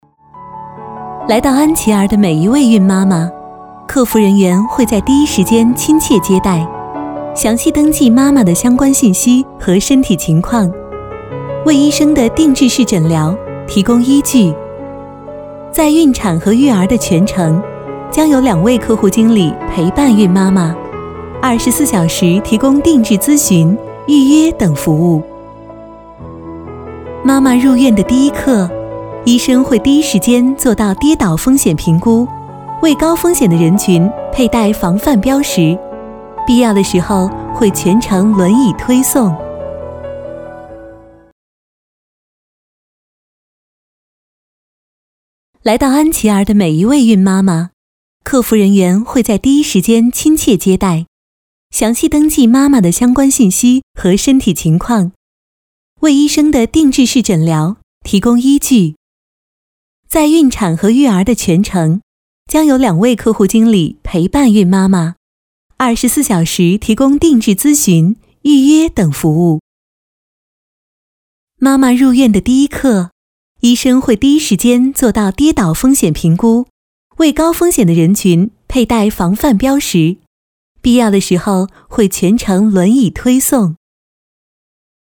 女国84_专题_医院_安琪儿妇产医院_甜美.mp3